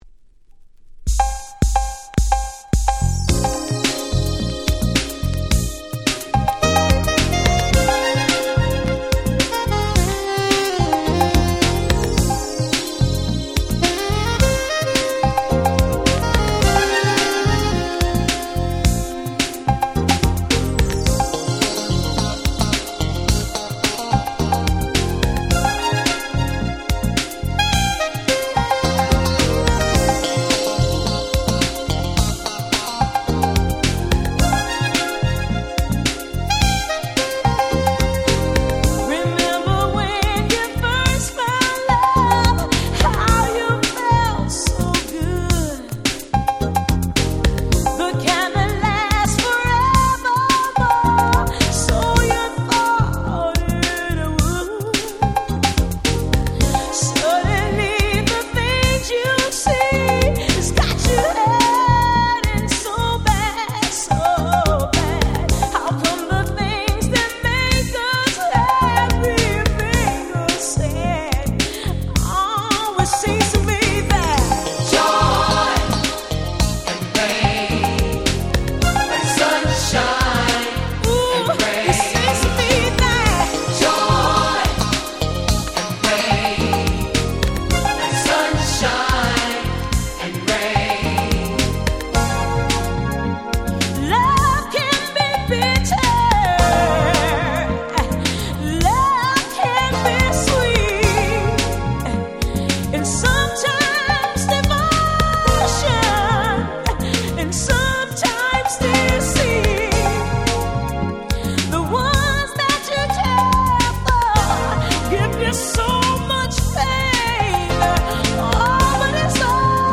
88' Super Nice Cover R&B / ブラコン！！